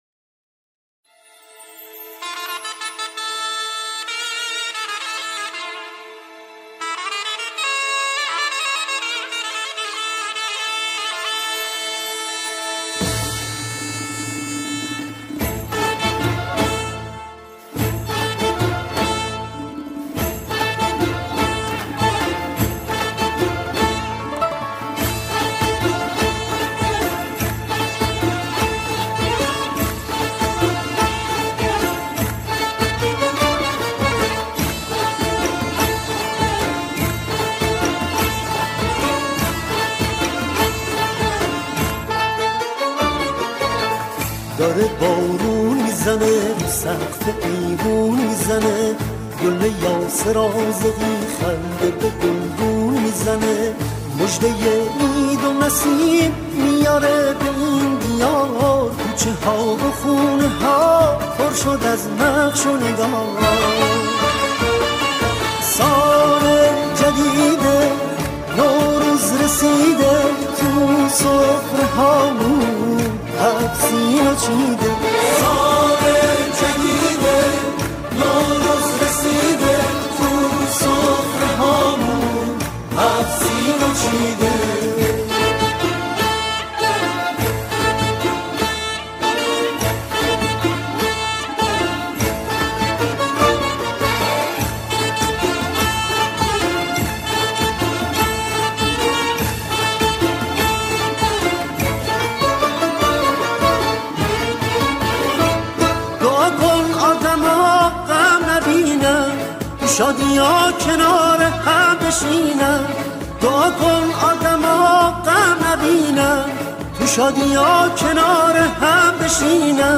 این قطعه، نمونه‌ای از جمعخوانی با اجرای دو خواننده اصلی است.